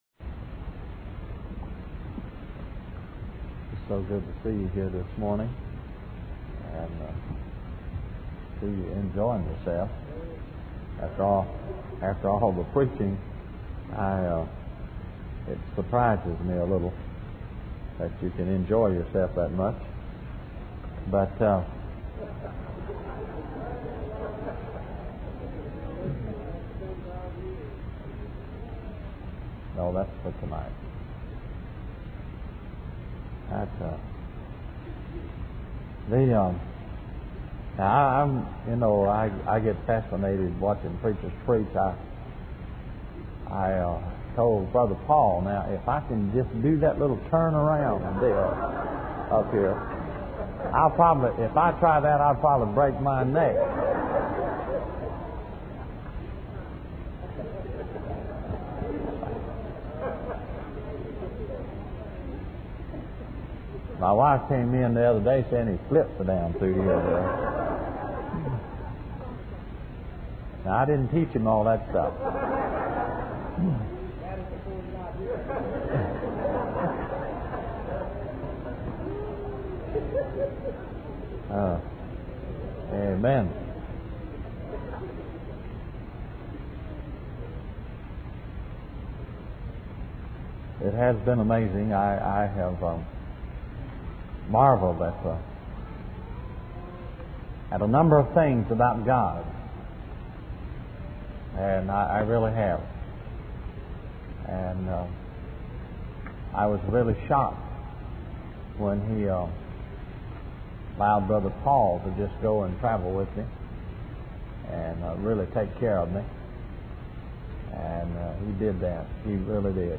In this sermon, the preacher emphasizes that God allows adversity in our lives for a purpose.